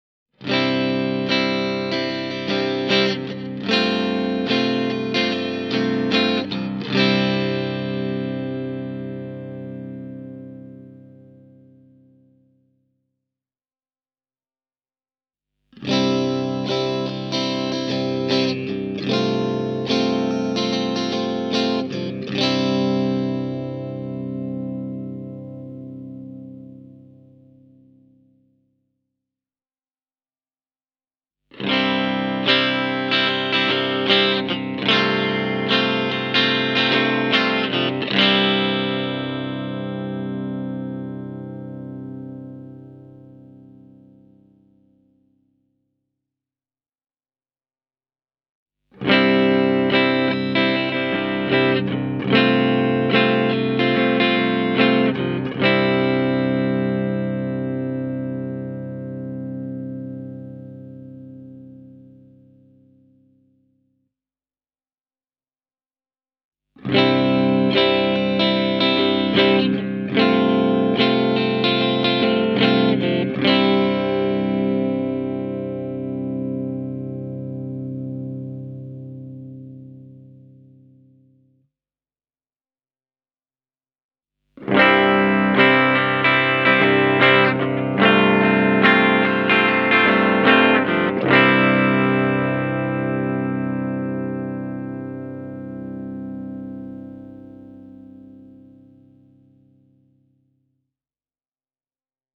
Bare Knuckles’ considerable expertise in the field of pickup making is clearly evident in the Nailbombs’ sounds, which is couples merciless power with a high degree of musicality.
Here’s a clean audio clip that starts off with the split settings (neck, both, bridge), before switching to the full humbuckers:
raato-penetraator-e28093-clean.mp3